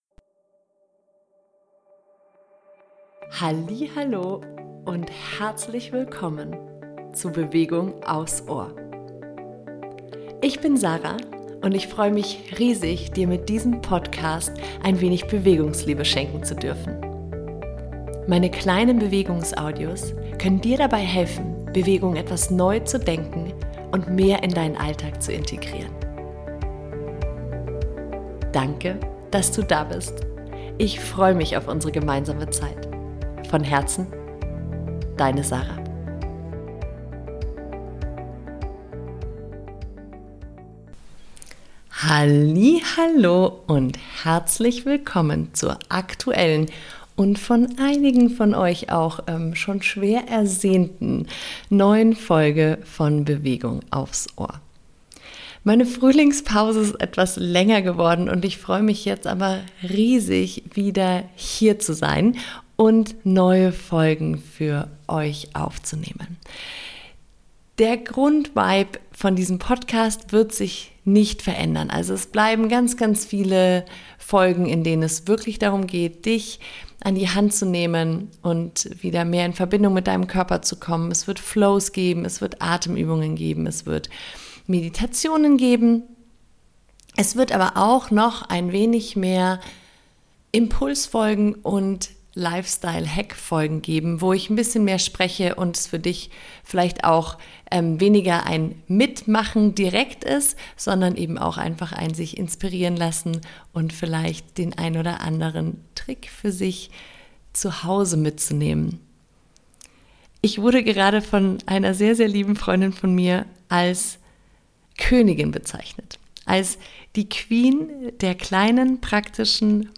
In dieser Folge teile ich meine absolute Superpower die mir hilft, meine kraftbringenden Routinen zu etablieren und beizubehalten! Zum verankern und verkörpern gibt es am Schluss der Folge eine Embodimentmeditation, die dir dabei hilft, das gehörte auch wirklich sinken zu lassen und somit vollständi...